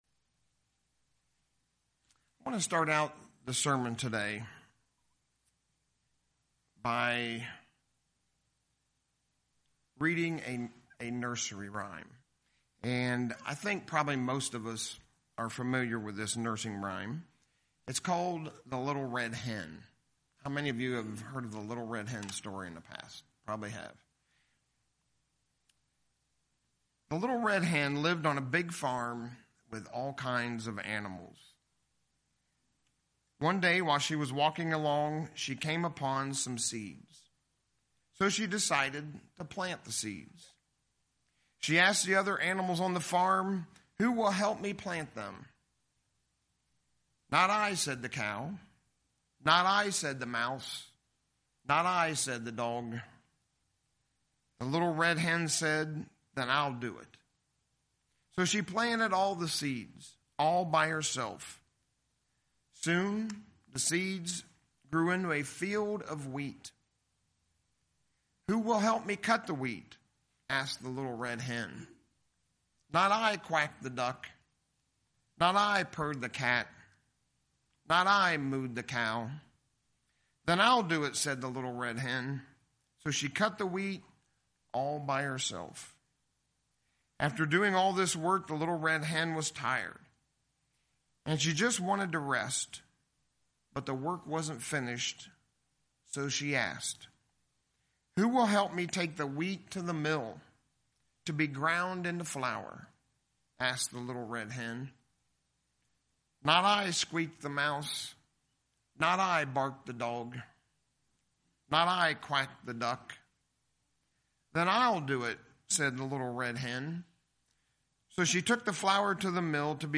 As we draw ever closer to the return of Jesus Christ, it is so important that God's people be preparing for His return, and the Kingdom of God! In the sermon today, we'll look at a couple very important aspects of preparing for the Kingdom of God.